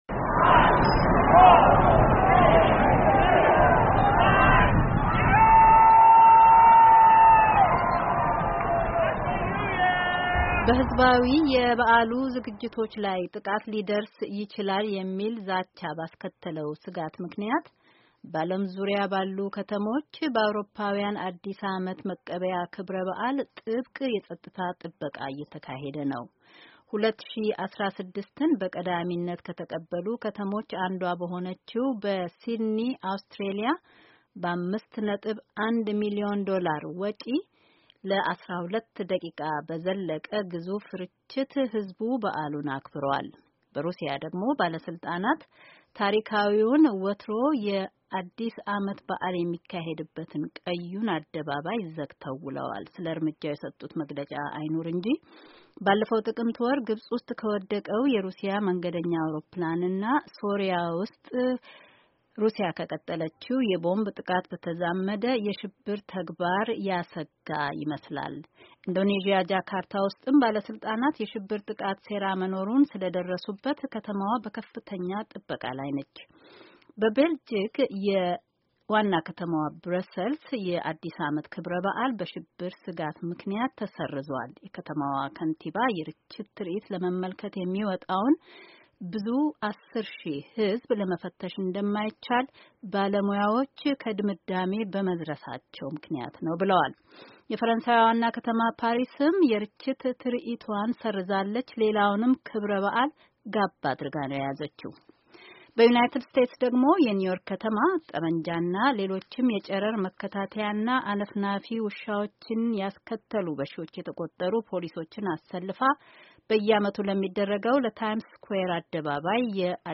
ፖሊሶችና የፌደራሉ ምርመራ ቢሮ በኒው ዮርኩም ሆነ በሌሎች ከተሞች ክብረበዓል ላይ በግልጽ የተሰነዘረ የሚታመን ዛቻ እንደሌለ ኣመልክትዋል። ሆኖም ከፓሪሱና ከካሊፎርኒያ ሳንበርናርዲኖ ከደረሱት የሽብር ጥቃቶች በሁዋላ ለማንኛውም በንቃት እንከታታላለን ብለዋል። የዜና ዘገባውን ለማዳመጥ ከዚህ በታች ያለውን የድምጽ ፋይል ያድምጡ።